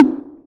Perc_(JW2).wav